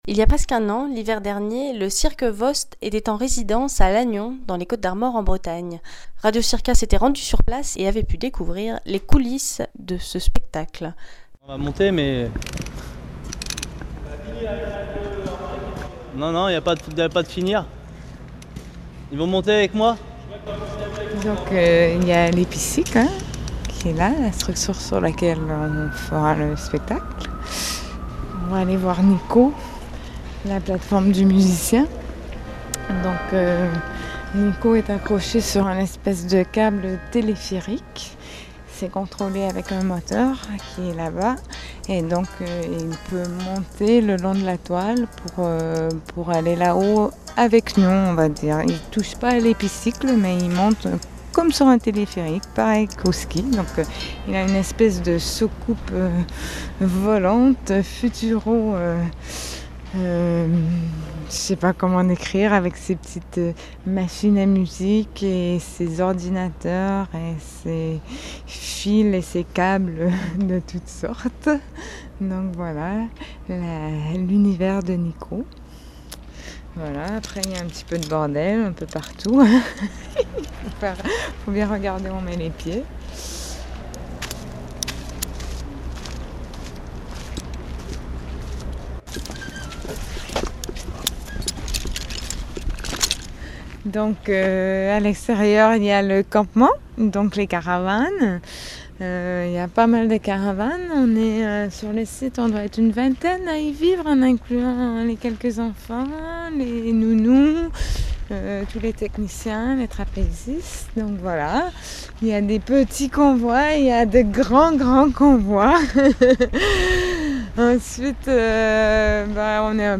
reportageCIRQUEVOSTenresidence.mp3